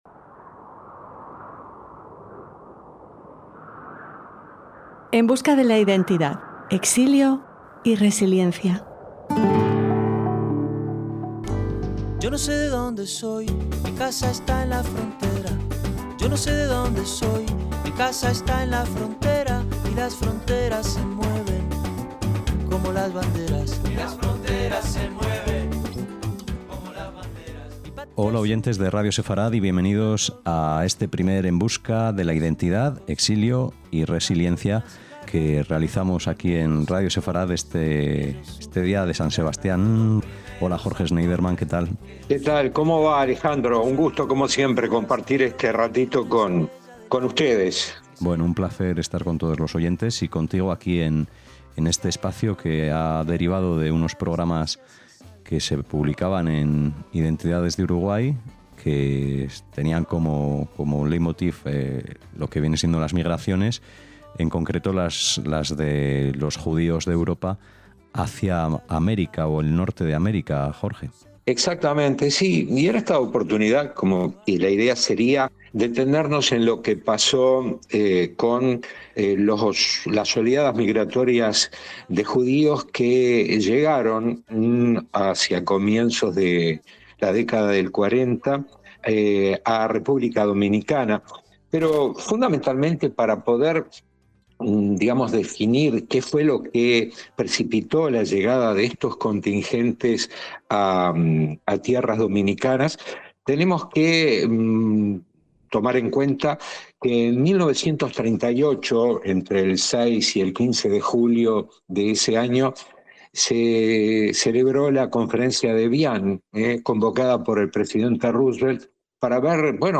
En este primer programa les invitamos a leer un artículo de la BBC sobre los colonos de Sosúa.